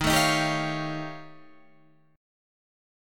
D# 9th Flat 5th